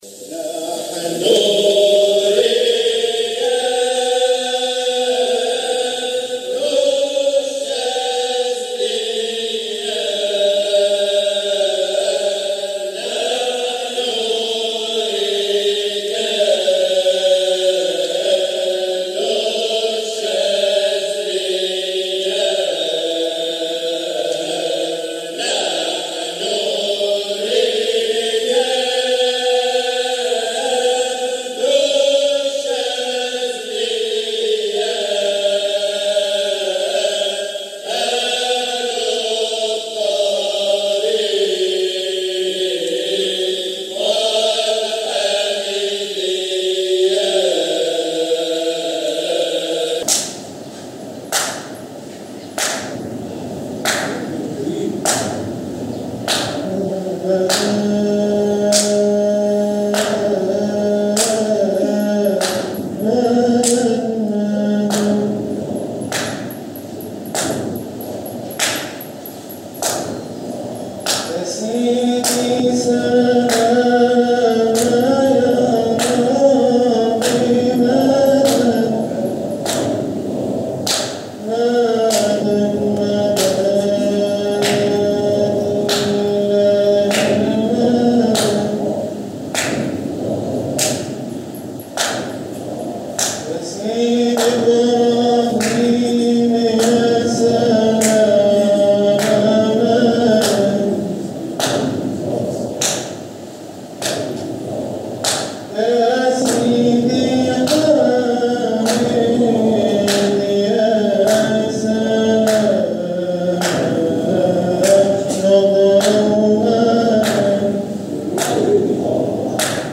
جزء من حلقة ذكر بمسجد مولانا المؤسس قُدس سره